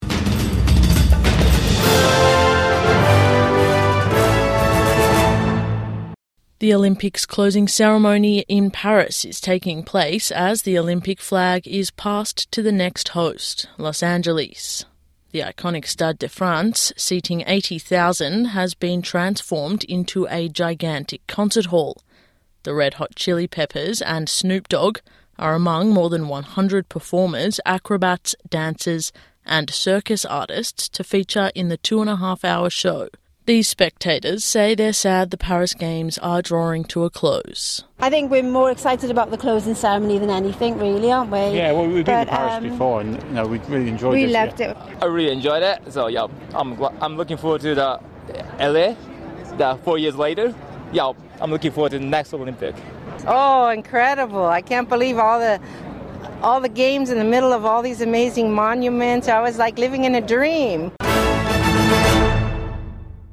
Snoop Dogg and Red Hot Chili Peppers perform at the Paris Games closing ceremony